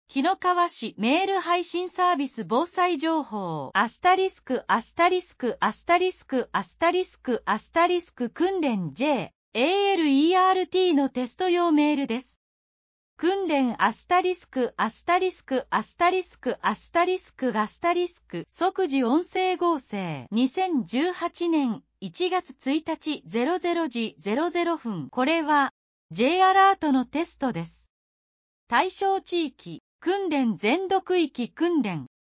【訓練】***** 「即時音声合成」 2018年01月01日00時00分 これは、Ｊアラートのテストです。